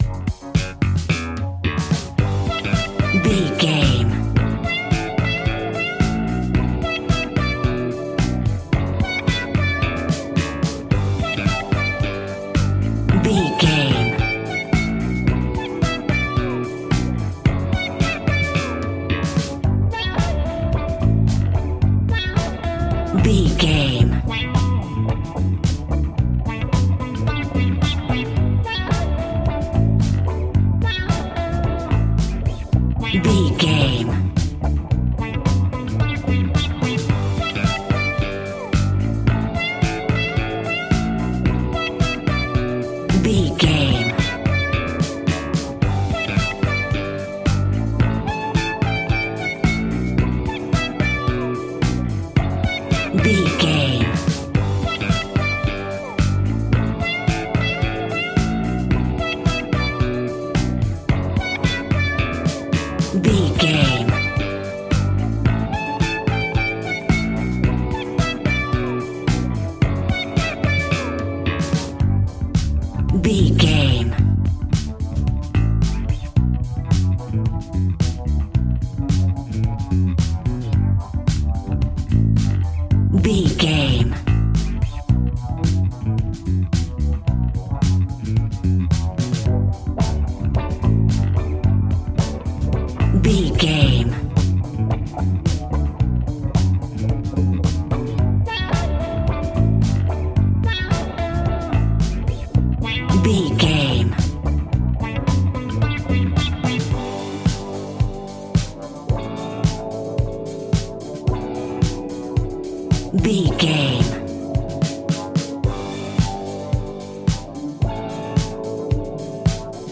Ionian/Major
D♭
house
synths
techno
trance
instrumentals